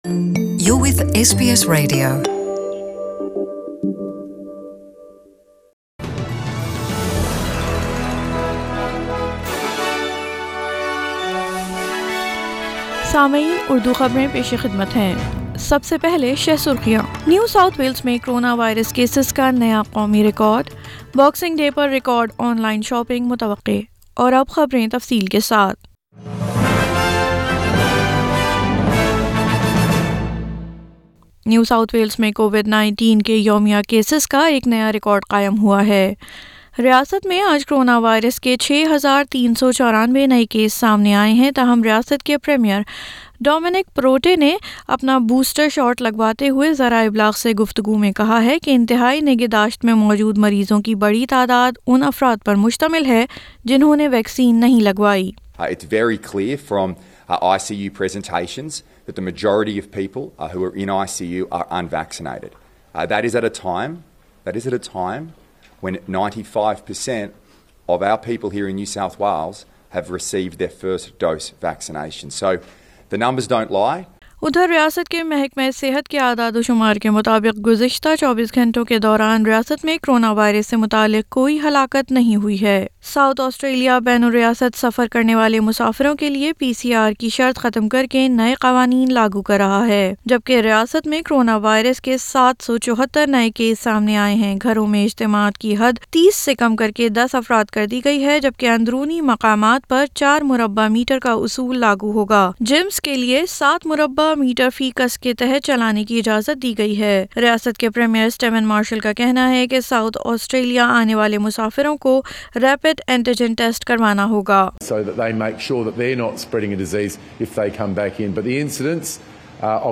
SBS Urdu News 26 December 2021